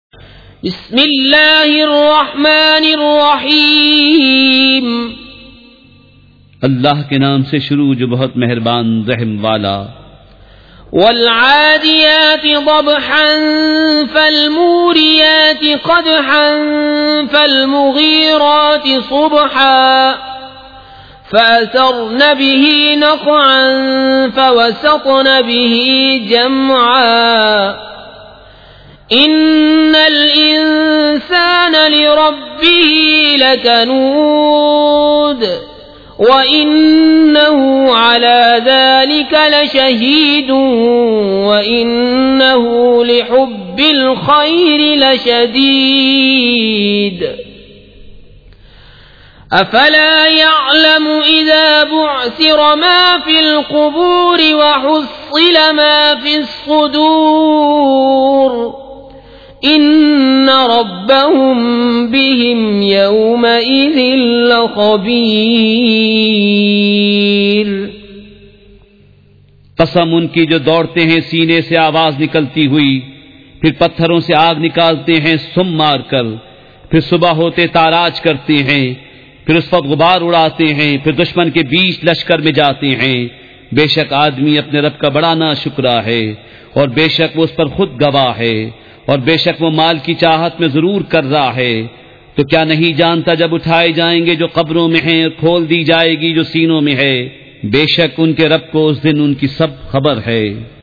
سورۃ العادیات مع ترجمہ کنزالایمان ZiaeTaiba Audio میڈیا کی معلومات نام سورۃ العادیات مع ترجمہ کنزالایمان موضوع تلاوت آواز دیگر زبان عربی کل نتائج 1637 قسم آڈیو ڈاؤن لوڈ MP 3 ڈاؤن لوڈ MP 4 متعلقہ تجویزوآراء